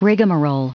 Prononciation du mot rigmarole en anglais (fichier audio)
Prononciation du mot : rigmarole